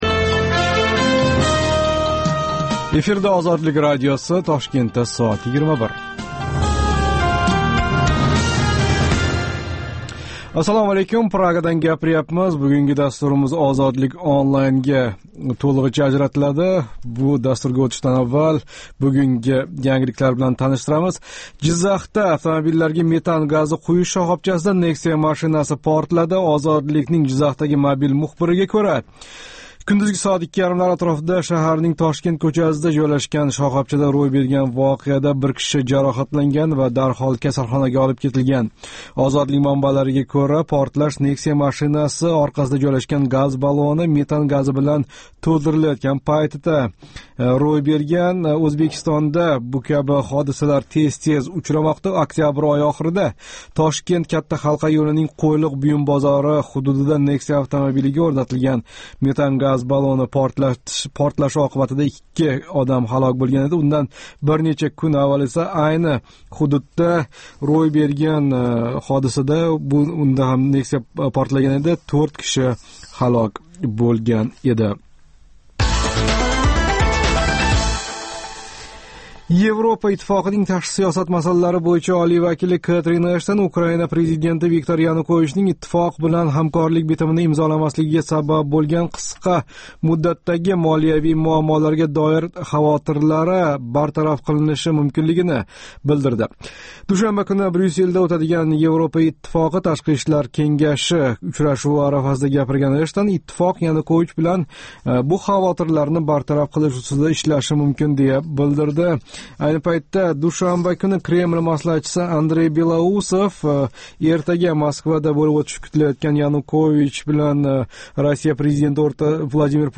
“Ozodlik Online” - Интернетдаги энг замонавий медиа платформаларни битта тугал аудио дастурга бирлаштирган Озодликнинг жонли интерактив лойиҳаси.